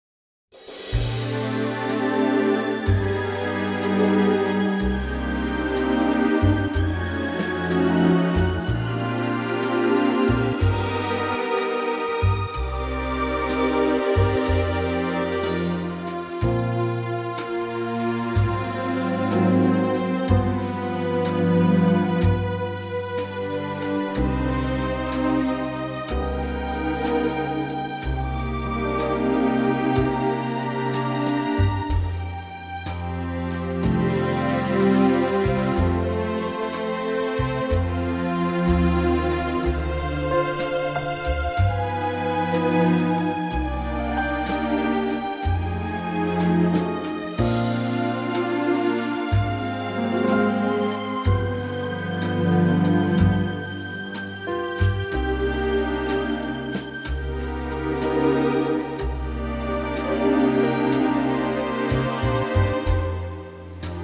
key - Bb - vocal range - F to Bb